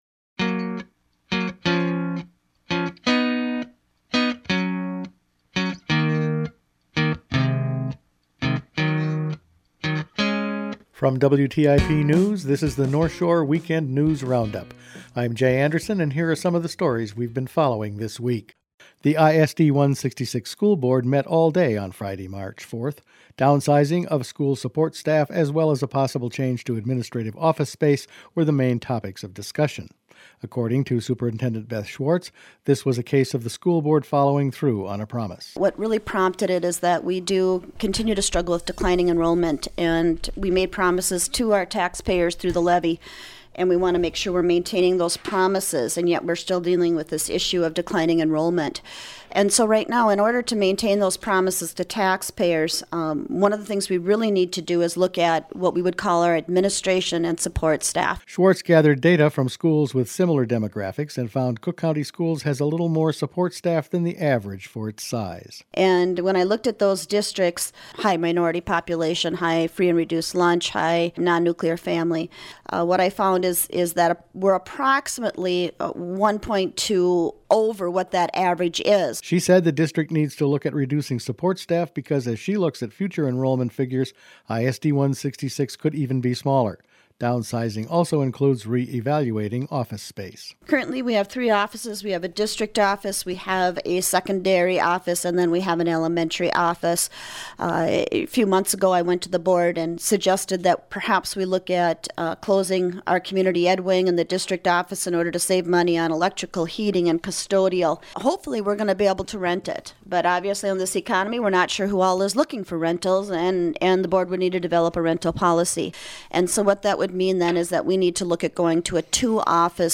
Each weekend WTIP news produces a round up of the news stories they’ve been following this week. School downsizing, Classic Car Show is moved, broadband workshops at Higher Ed and Township elections were all in this week’s news.